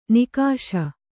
♪ nikāśa